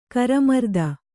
♪ karamarda